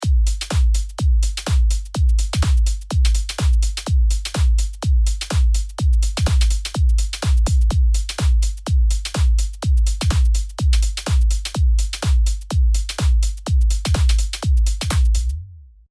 まず、ドライのドラムを聞いてみましょう。